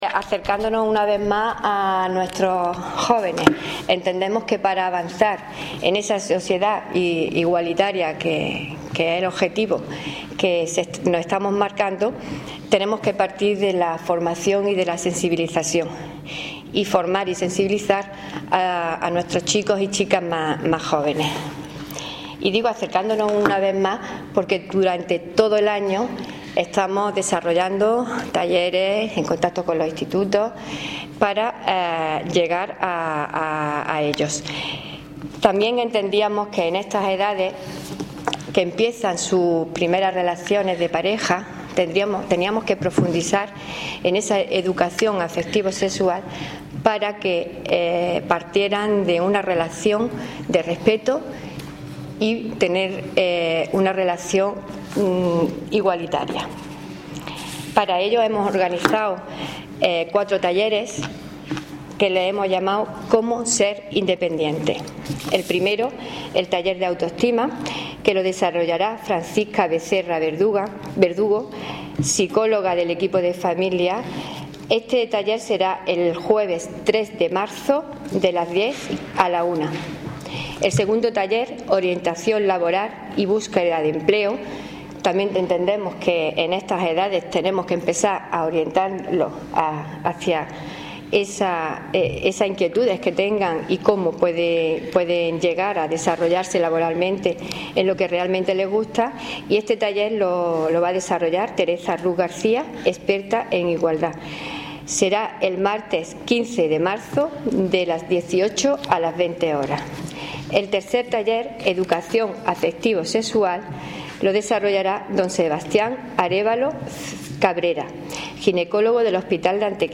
Cortes de voz
Audio: concejala de Derechos Sociales (I)   2031.84 kb  Formato:  mp3